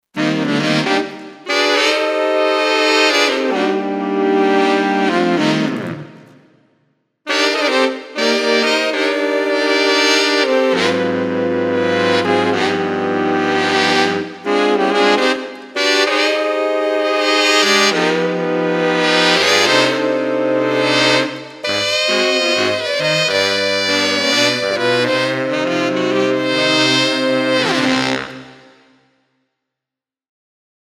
ça ne sera jamais un vrai son de sax, mais unanimement reconnu, voici le meilleur rapport qualité prix de ce qui se fait en soft:
sax
alors bien sûr, quand on le sait, c'est évident que c'est un synthé, mais en aveugle, j'ai jamais vu quelqu'un en être certain !